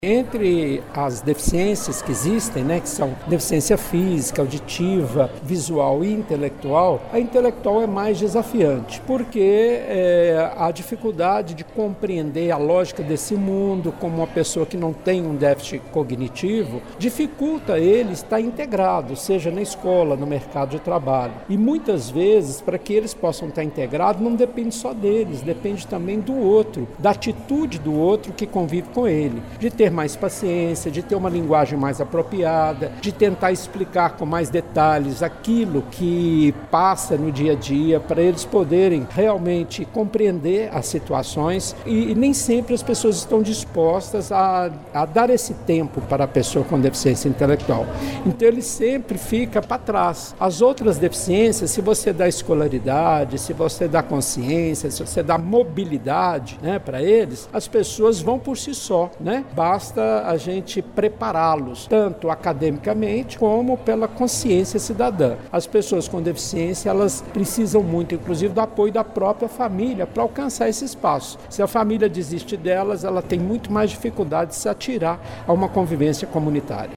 Na abertura da programação, o deputado federal Eduardo Barbosa (PSDB) fez uma importante palestra pública destacando a importância da cidadania da pessoa intelectual no município.